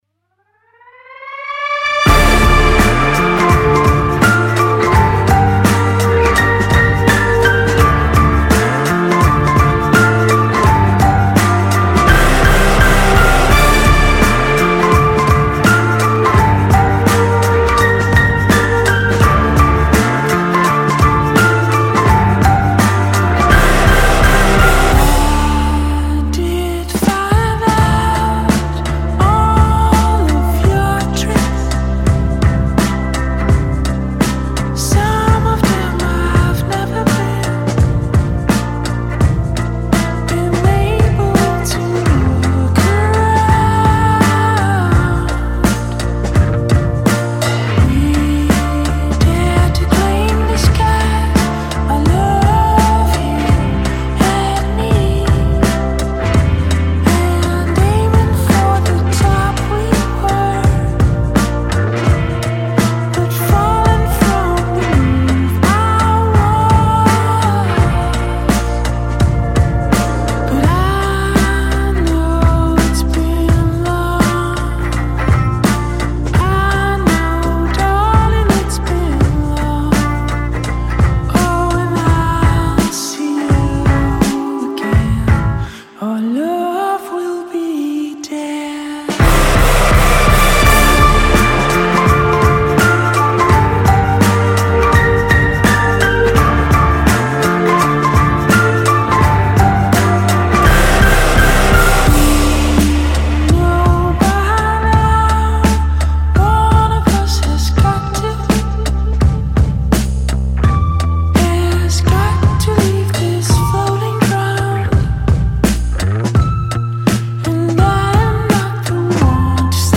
‘Trippop’ band